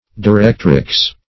Directrix \Di*rect"rix\, n.; pl.